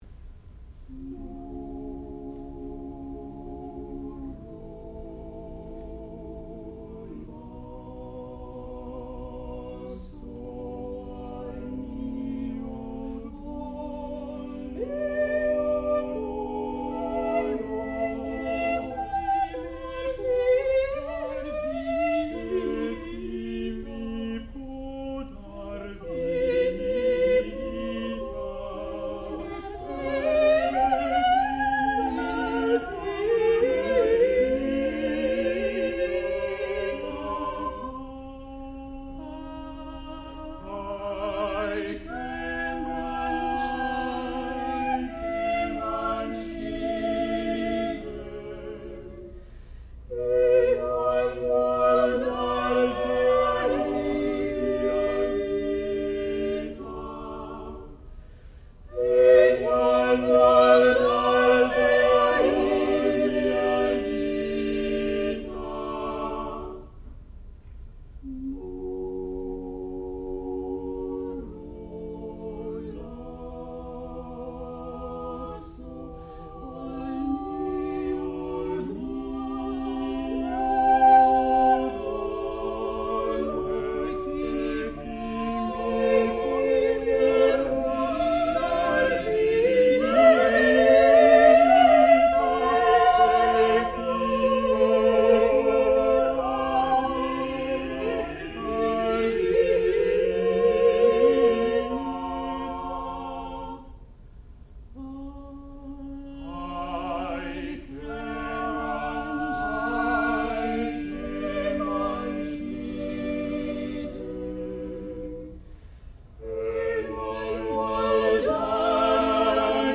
sound recording-musical
classical music
baritone
mezzo-soprano
tenor